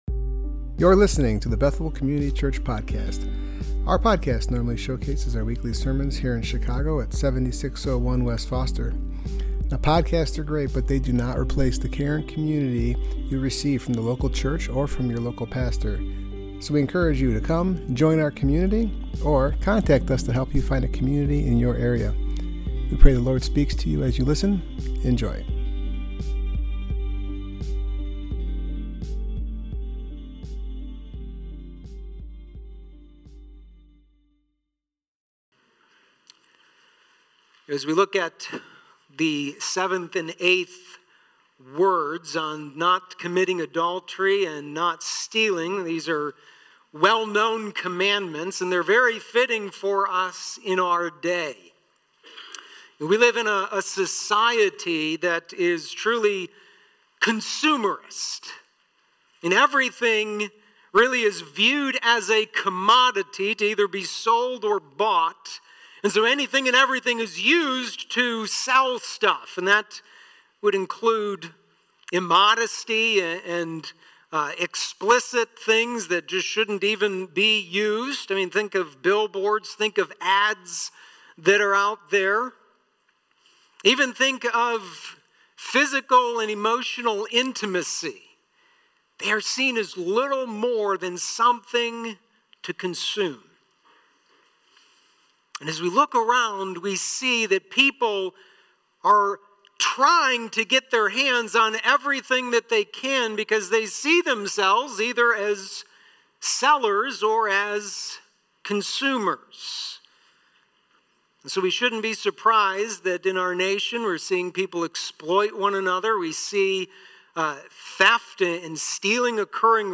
Service Type: Worship Gathering Topics: marriage , sexual immorality , stealing , ten commandments